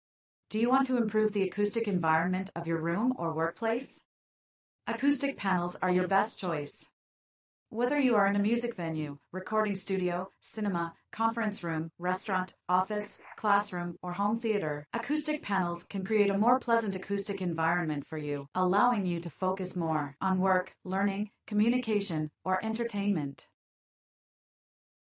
Sound effect of using INTCO Acoustic Panels
denoise.mp3